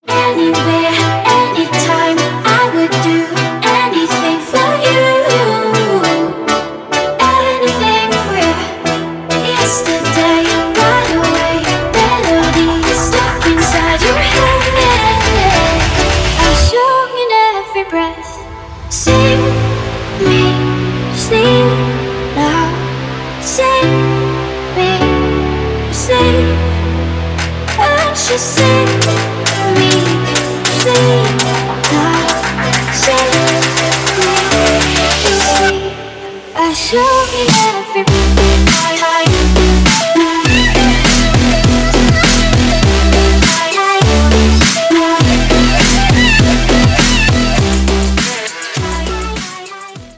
dance
club